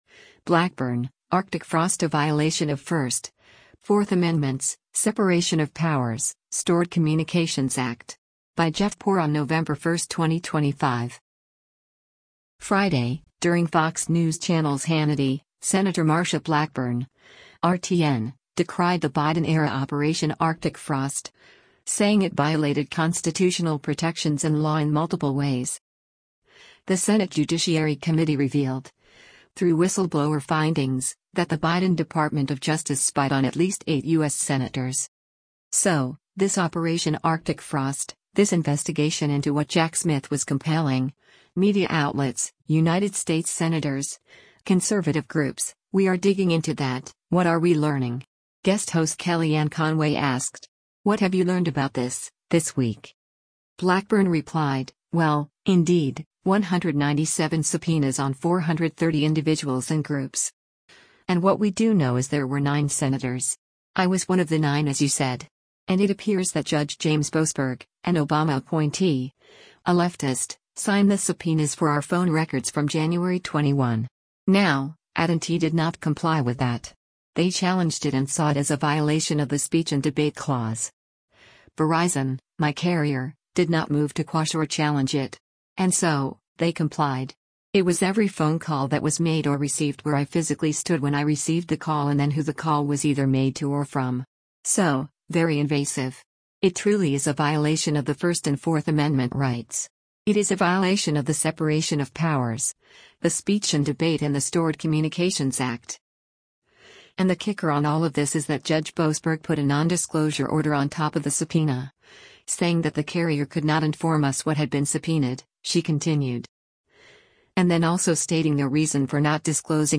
Friday, during Fox News Channel’s “Hannity,” Sen. Marsha Blackburn (R-TN) decried the Biden-era Operation Arctic Frost, saying it violated constitutional protections and law in multiple ways.